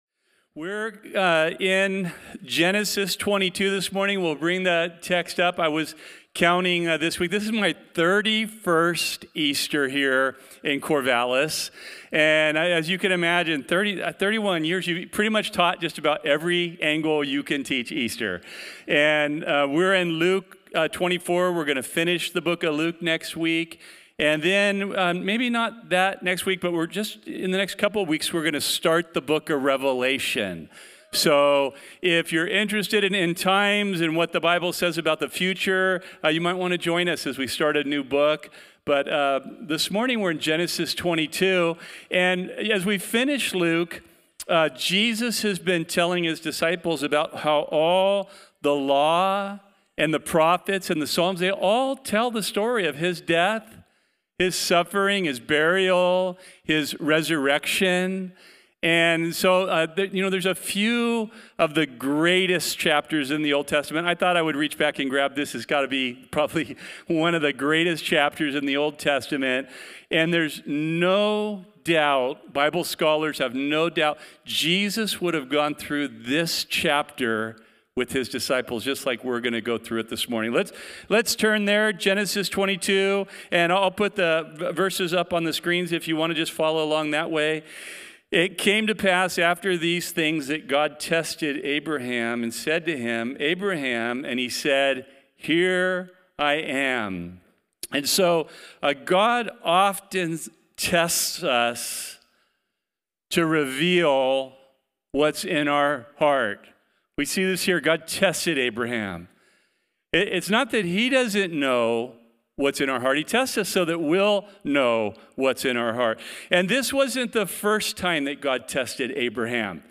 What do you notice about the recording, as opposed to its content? The Bible study given at Calvary Chapel Corvallis on Sunday, April 20, 2025.